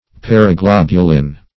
Paraglobulin \Par`a*glob"u*lin\